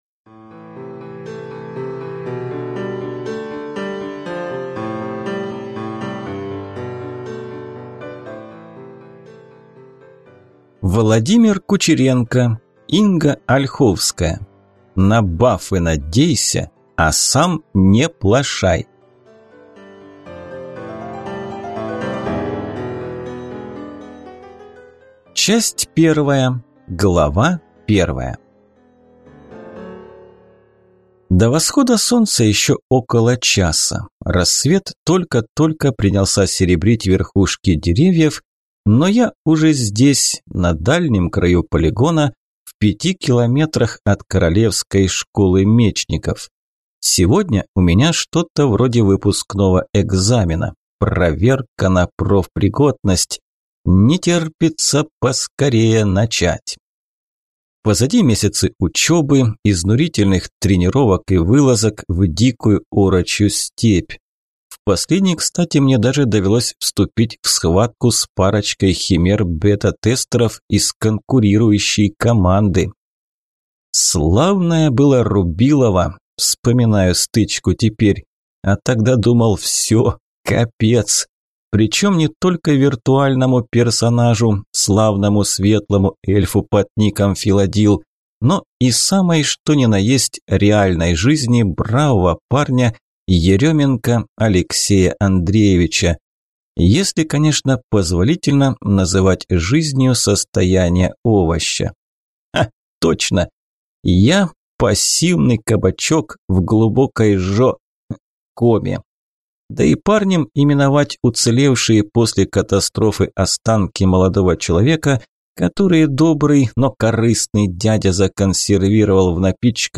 Аудиокнига На баффы надейся, а сам не плошай | Библиотека аудиокниг